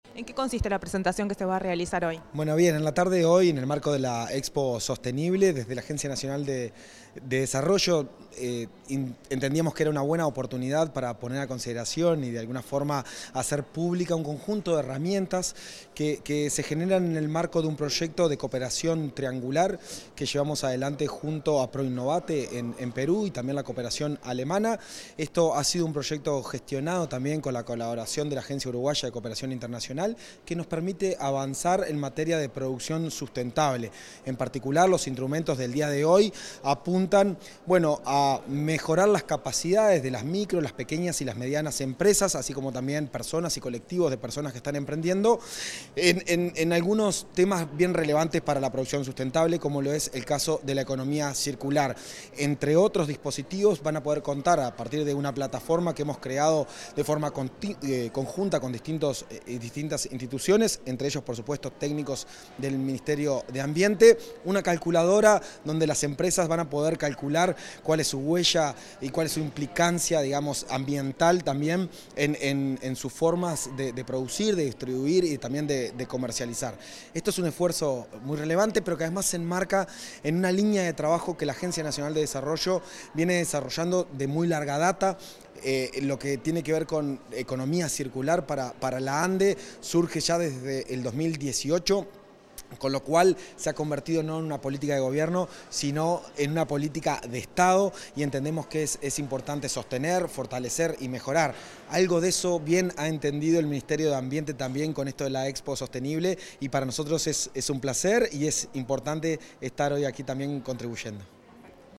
Declaraciones del Juan Ignacio Dorrego
El presidente de ANDE, Juan Ignacio Dorrego, dialogó con la prensa en tras participar del evento Promoviendo la Economía Circular: Presentación del